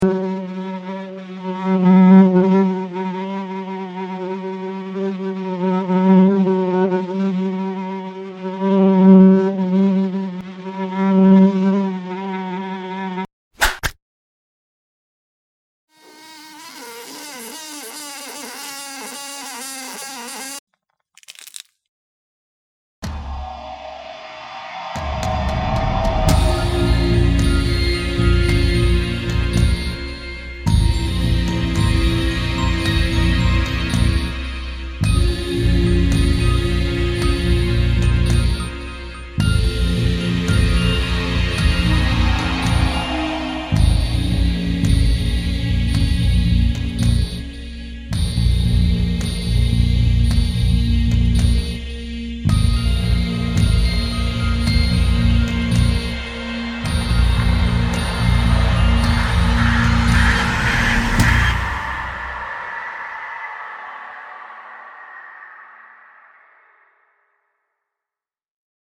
Category: Sound FX   Right: Personal